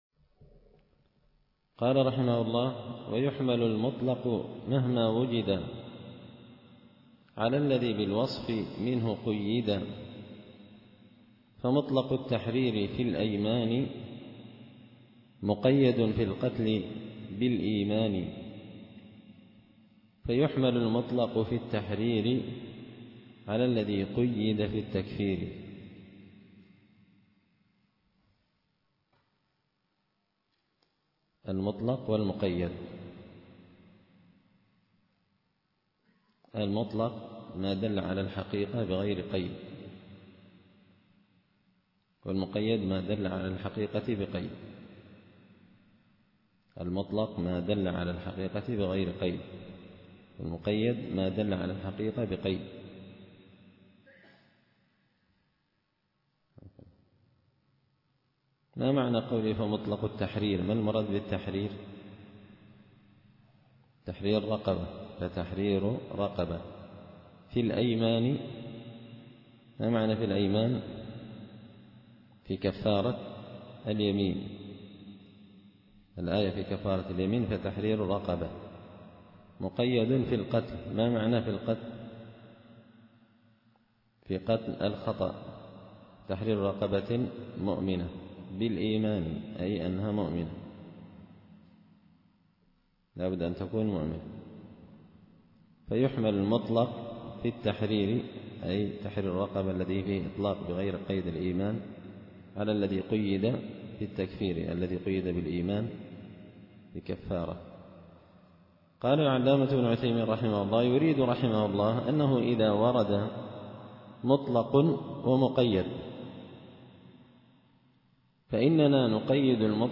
التعليقات على نظم الورقات ـ الدرس 28
دار الحديث بمسجد الفرقان ـ قشن ـ المهرة ـ اليمن